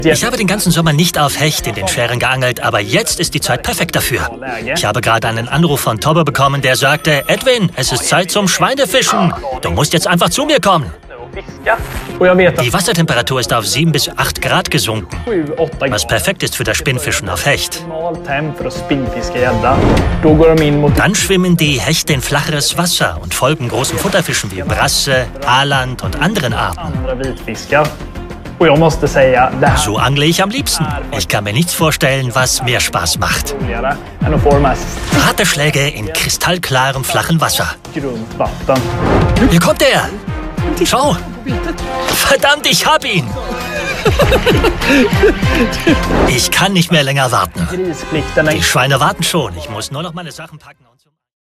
Male
Assured, Character, Cheeky, Confident, Cool, Engaging, Friendly, Natural, Smooth, Witty, Versatile, Authoritative, Corporate, Warm
Microphone: Neumann U87